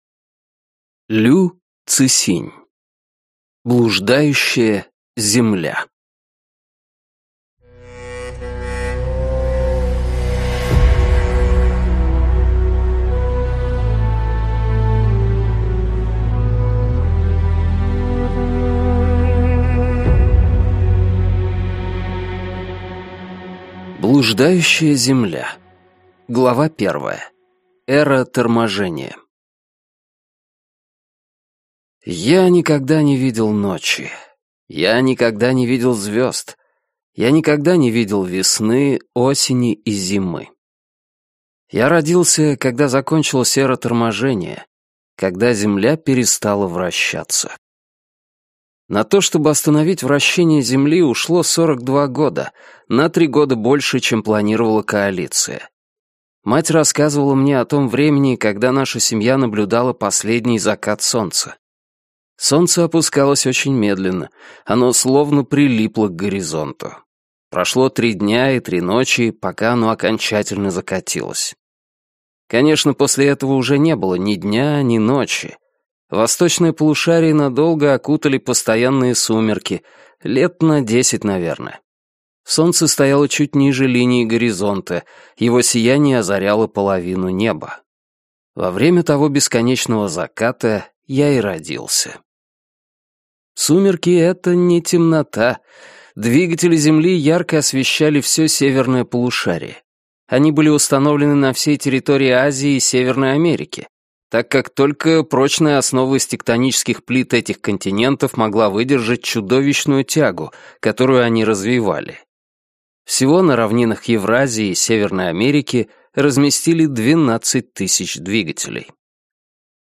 Прослушать фрагмент аудиокниги Блуждающая Земля Лю Цысинь Произведений: 5 Скачать бесплатно книгу Скачать в MP3 Вы скачиваете фрагмент книги, предоставленный издательством